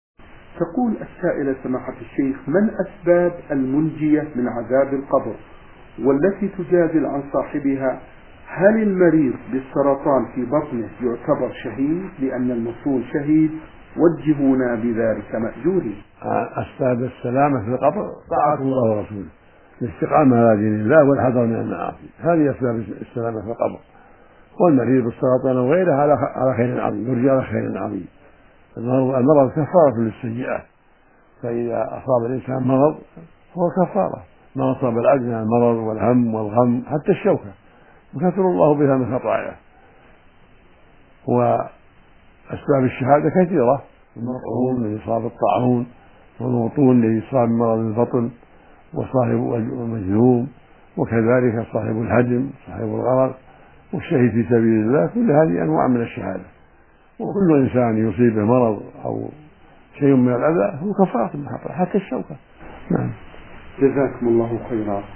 شبكة المعرفة الإسلامية | الفتاوى | المنجيات في القبر.. وهل من مات بسبب السرطان شهيد؟